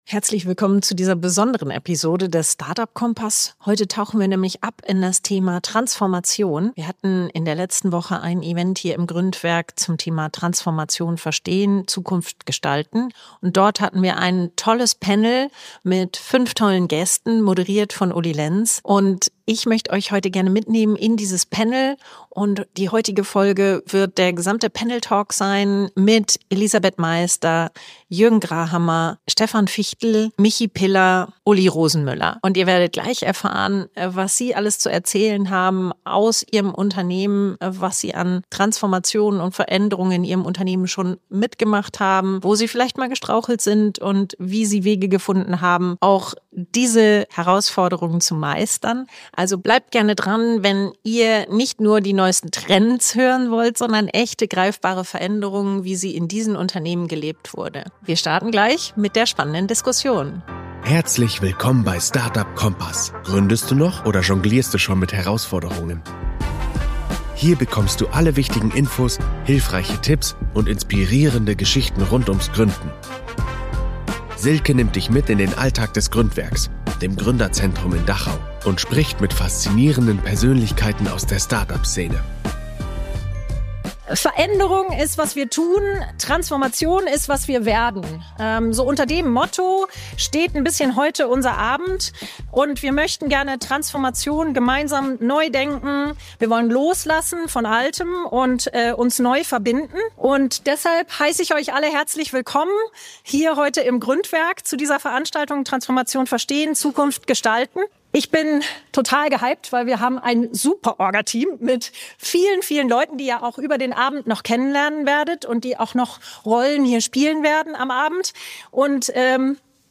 In dieser besonderen Folge hörst du den Mitschnitt eines Live-Panels aus dem Gründwerk. Fünf UnternehmerInnen teilen ihre ganz persönlichen Erfahrungen mit Veränderung, Kulturwandel und Digitalisierung.